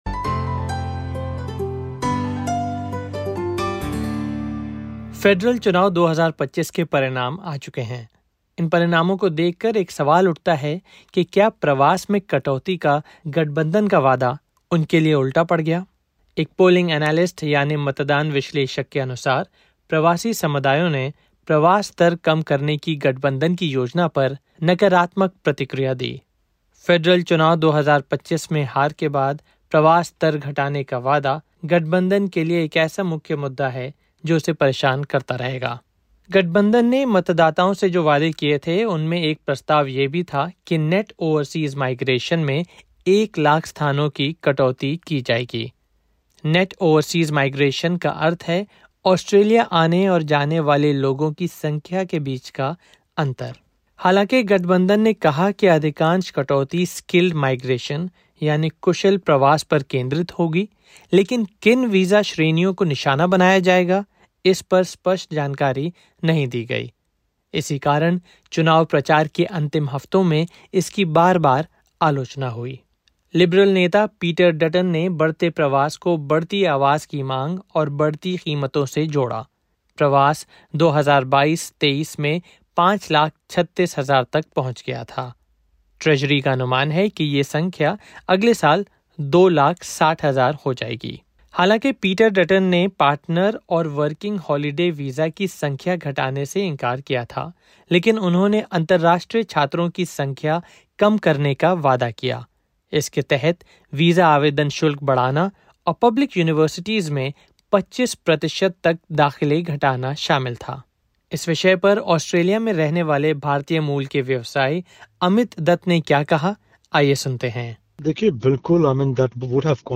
In this podcast by SBS Hindi, members of the Indian-origin community in Australia react to the Coalition’s pledge to cut migration, which some believe contributed to its election loss. A polling analyst also highlights that many people in the community shared concerns over the lack of clarity in the proposed migration cut. Voters suggest that if there had been more detail or a clear alternative, the community’s response — and possibly the election result — could have been different.